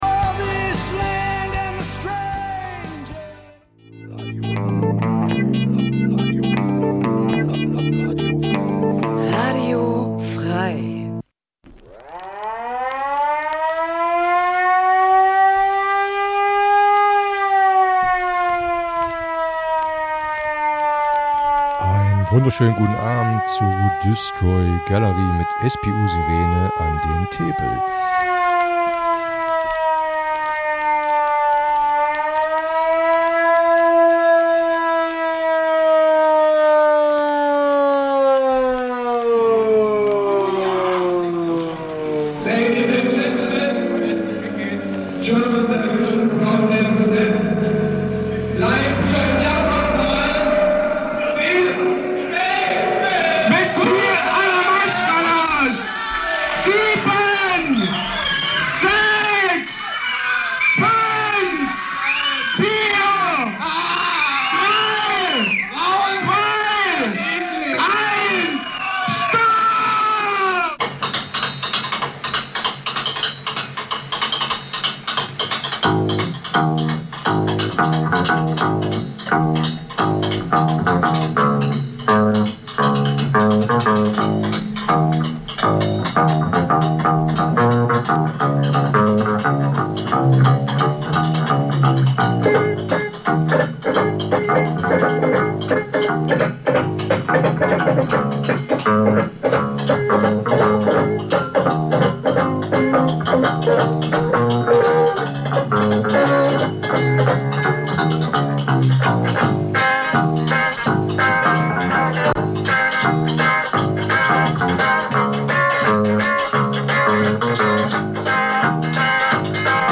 Punk '77 - '79 Dein Browser kann kein HTML5-Audio.